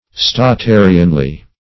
statarianly - definition of statarianly - synonyms, pronunciation, spelling from Free Dictionary Search Result for " statarianly" : The Collaborative International Dictionary of English v.0.48: Statarianly \Sta*ta"ri*an*ly\, adv. Fixedly; steadily.